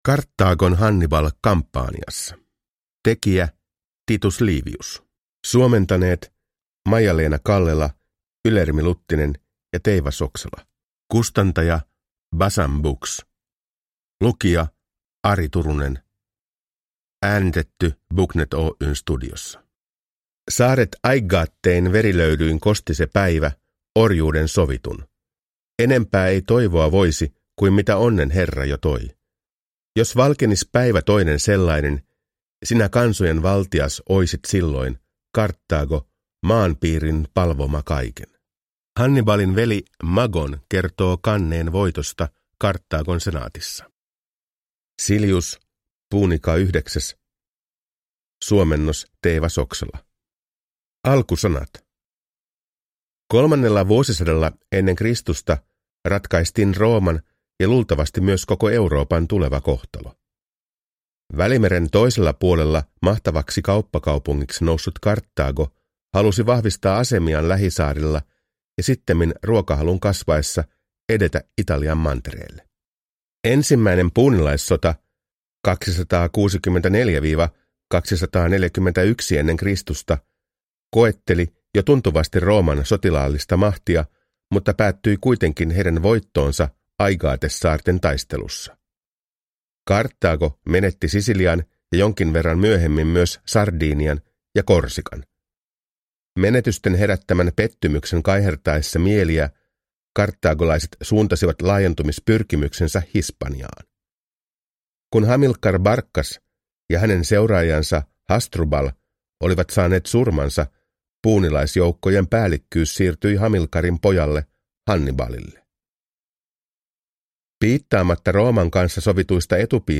Karthagon Hannibal Campaniassa – Ljudbok